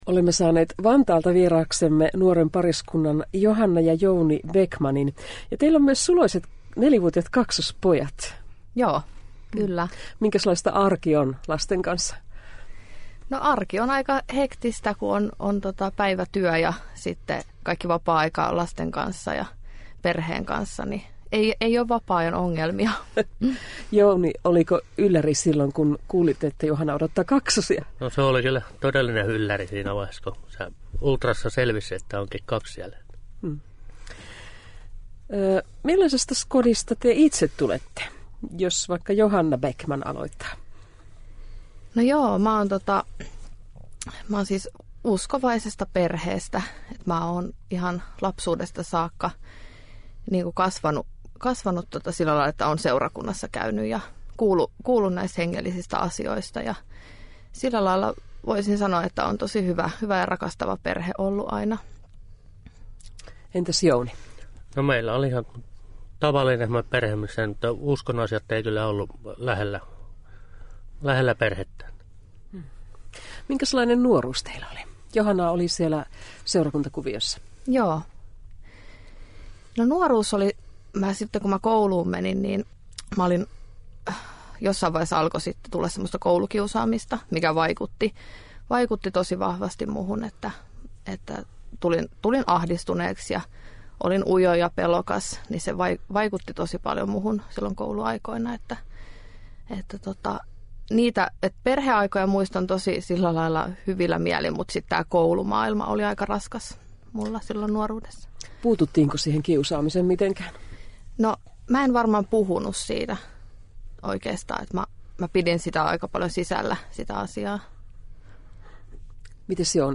Kuuntele heidän haastattelunsa: